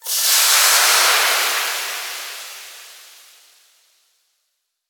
cooking_sizzle_burn_fry_08.wav